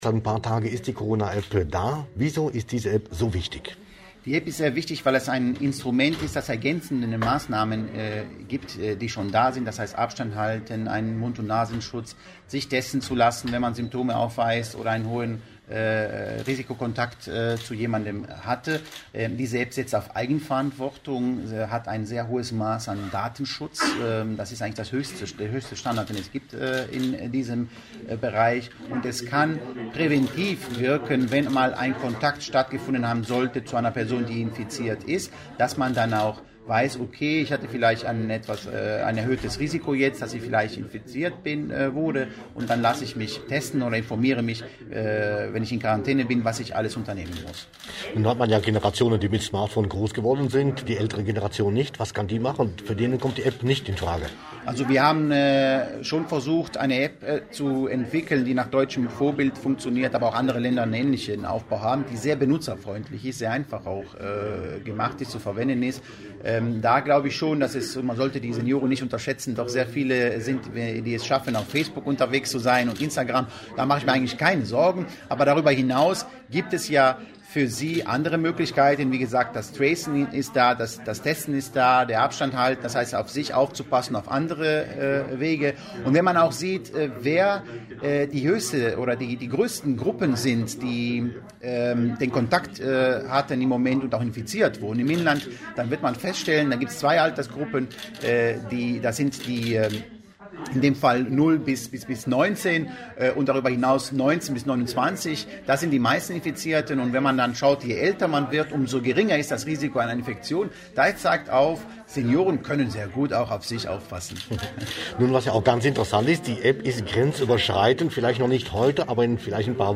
Interview mit Minister Anonios Antoniadis